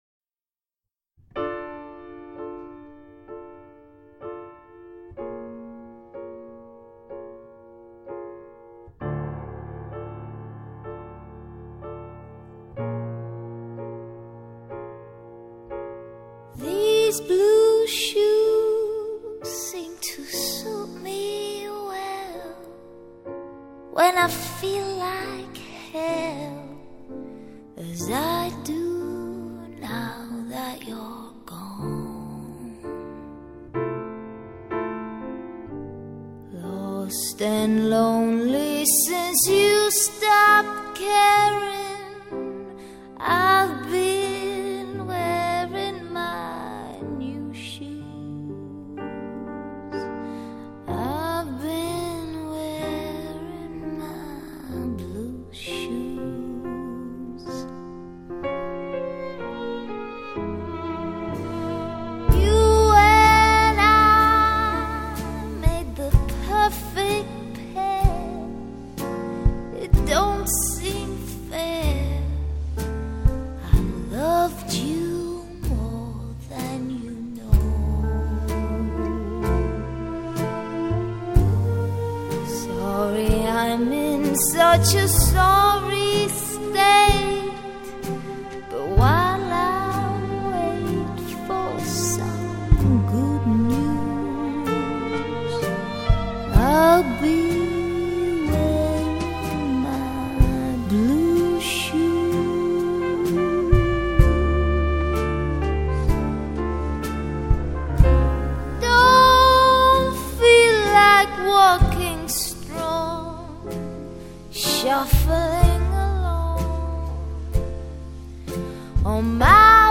爵士小品